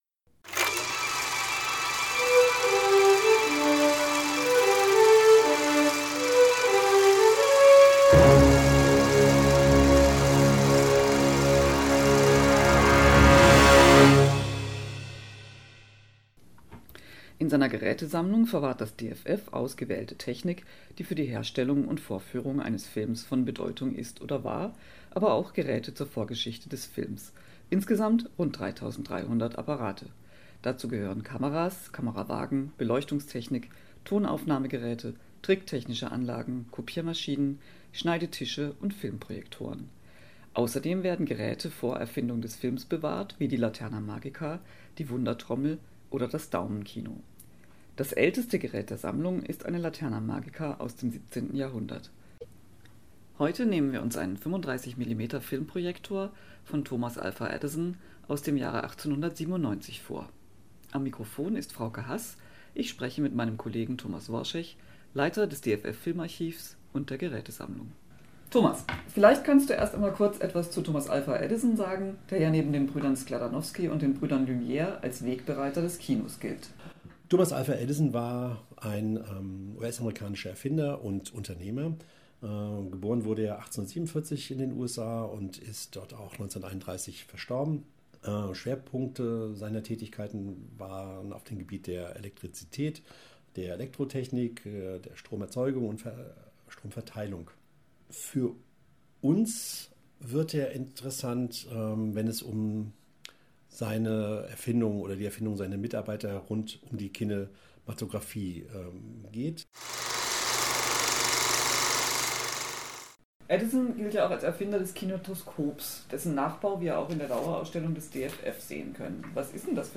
In diesem Audiobeitrag spricht